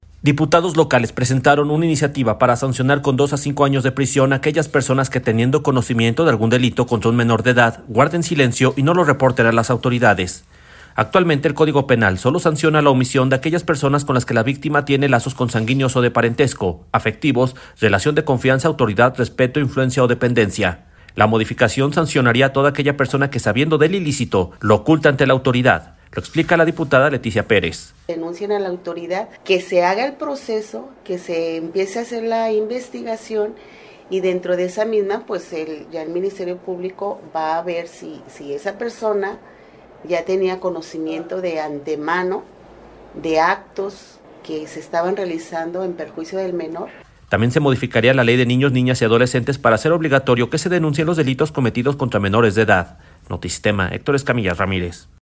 La modificación sancionaría a toda aquella persona que sabiendo del ilícito, lo oculte ante las autoridades. Lo explica la diputada Leticia Pérez.